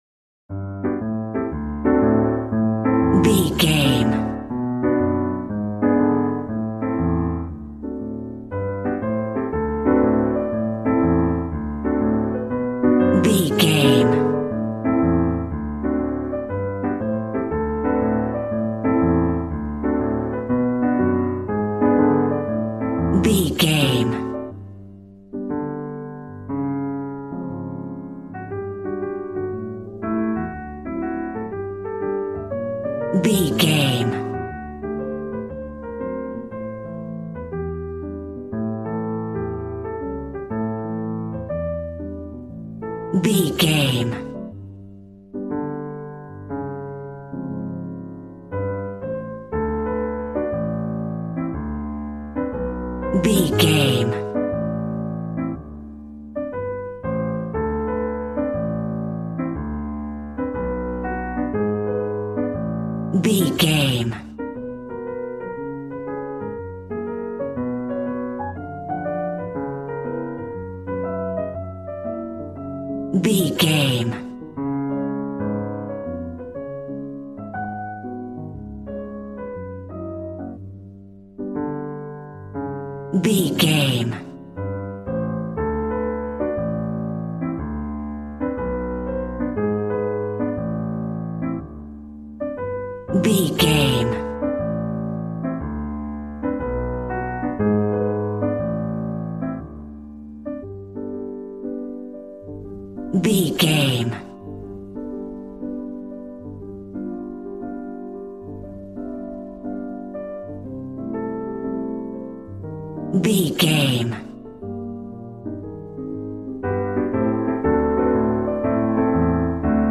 Smooth jazz piano mixed with jazz bass and cool jazz drums.,
Ionian/Major
smooth
piano
drums